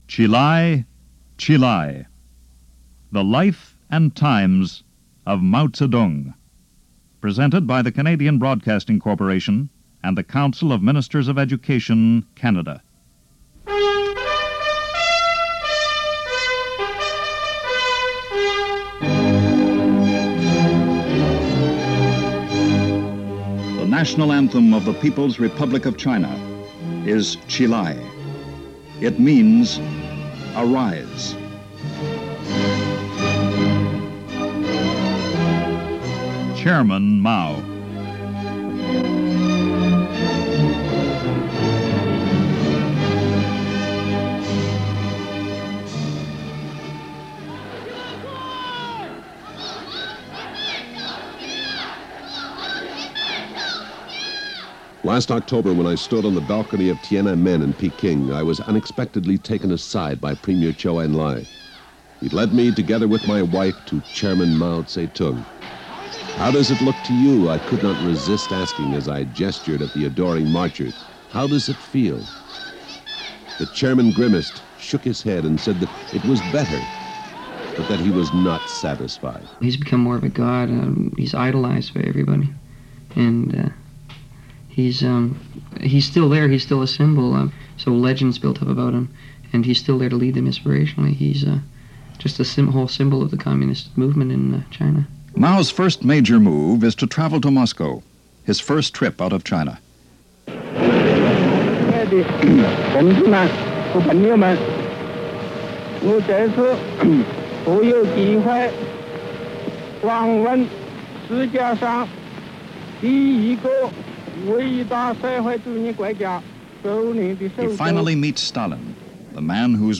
The Life And Times Of Chairman Mao - CBC Radio documentary circa 1975 - the rise to power of Mao-Tes Tung.